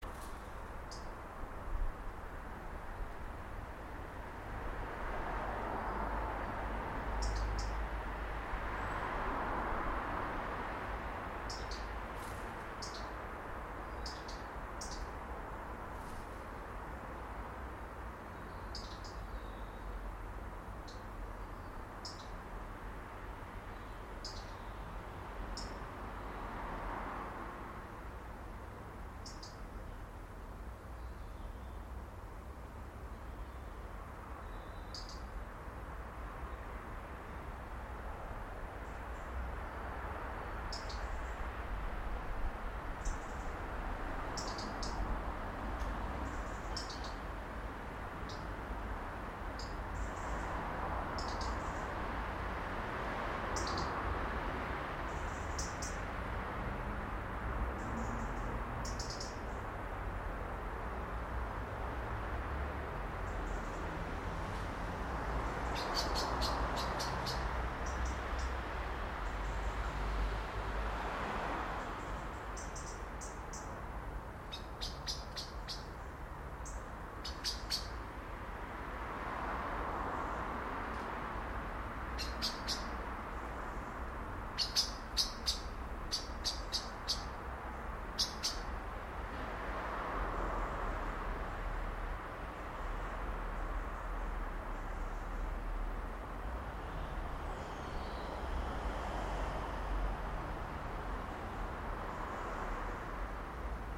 6.40 Birdsong with alarmed blackbird 12 October 2012